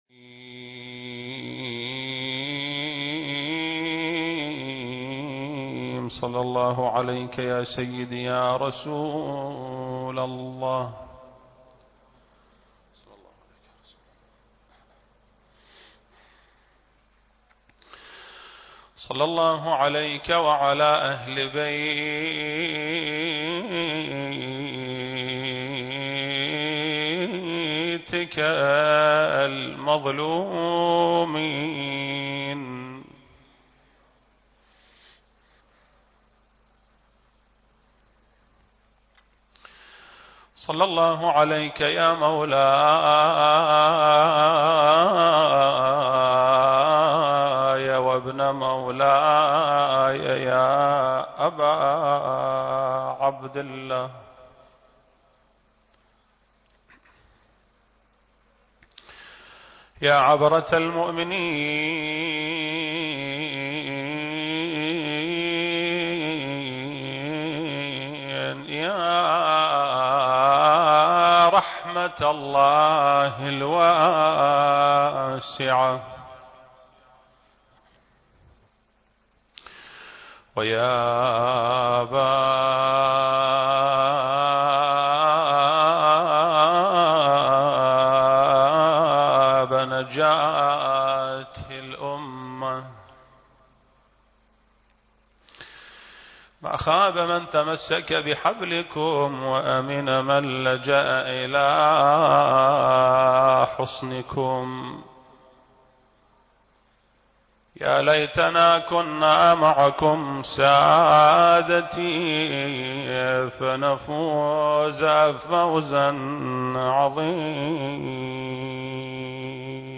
المكان: مسجد آل محمد (صلّى الله عليه وآله وسلم) - البصرة التاريخ: شهر رمضان المبارك - 1442 للهجرة